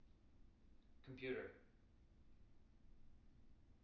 tng-computer-325.wav